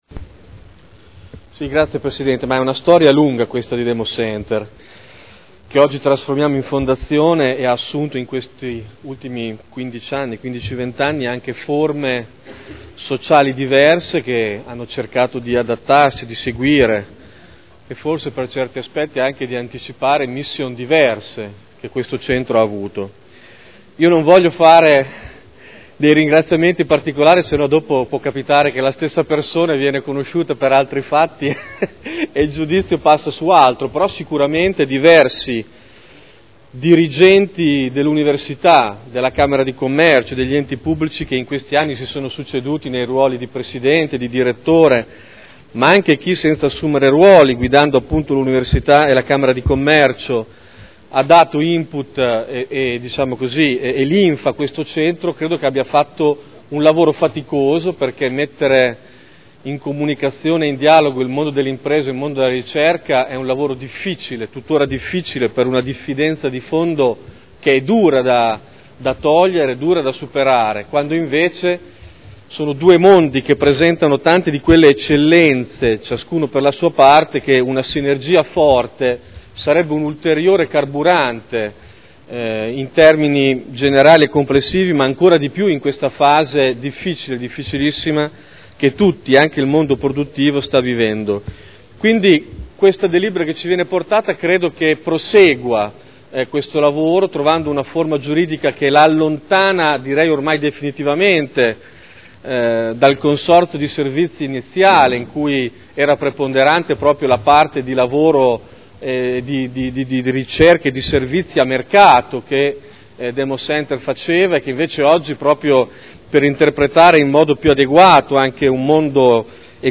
Davide Torrini — Sito Audio Consiglio Comunale
Dichiarazione di voto su proposta di deliberazione. Trasformazione di Democenter-Sipe da Società Consortile a Responsabilità Limitata a Fondazione – Approvazione dello statuto